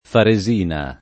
Faresina [ fare @& na ]